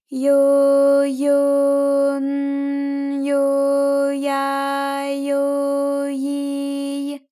ALYS-DB-001-JPN - First Japanese UTAU vocal library of ALYS.
yo_yo_n_yo_ya_yo_yi_y.wav